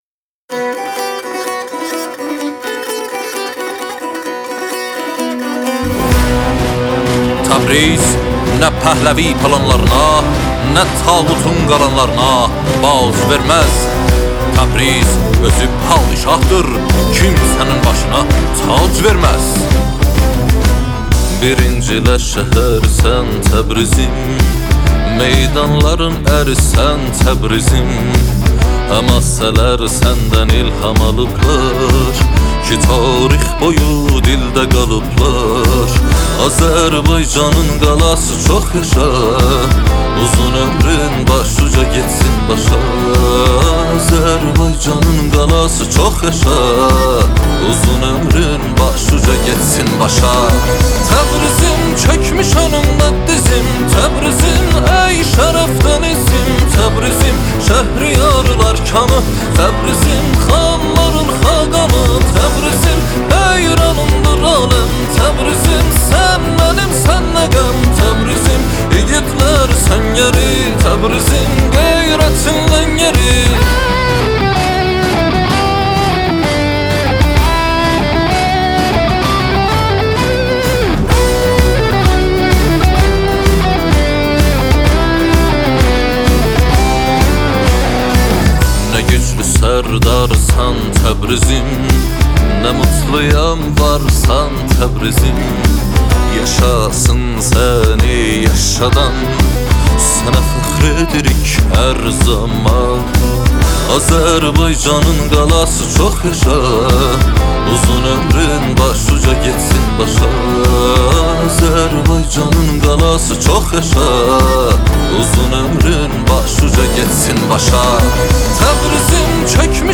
آهنگ آذربایجانی آهنگ شاد آذربایجانی آهنگ هیت آذربایجانی